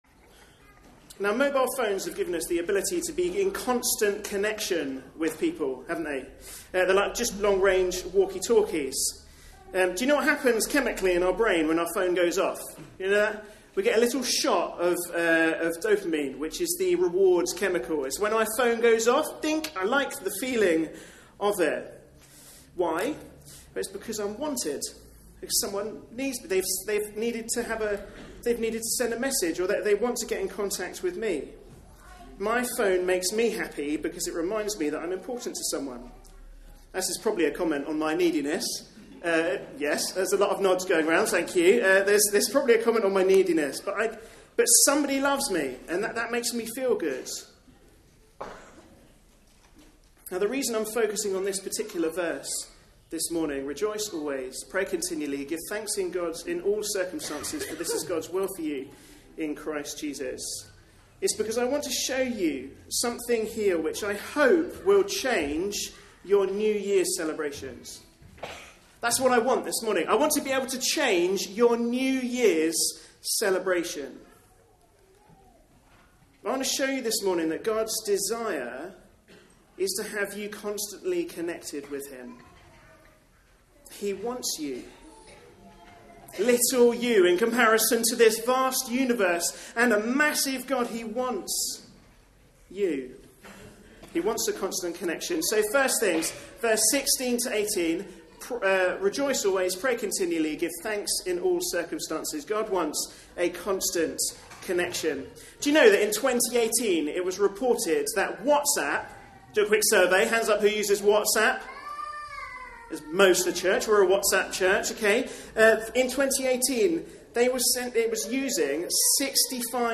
A sermon preached on 29th December, 2019.